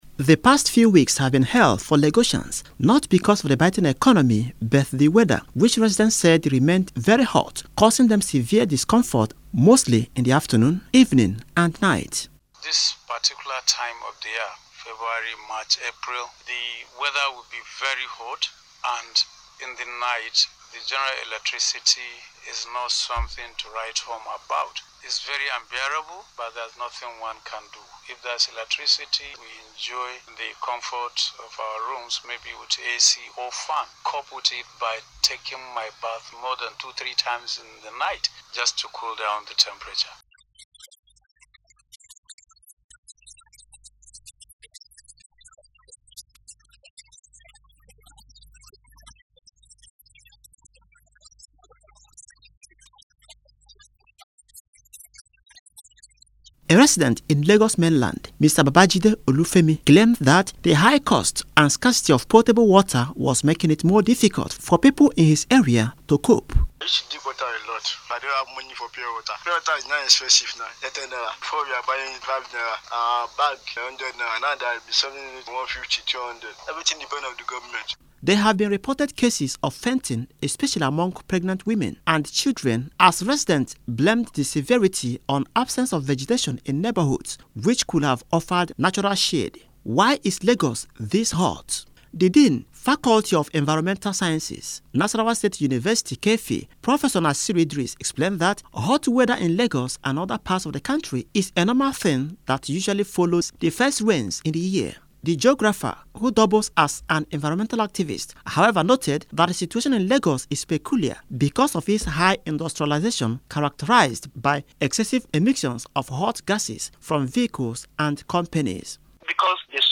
Radio Report: Lagosians want answers to weather challenges on World Meterological Day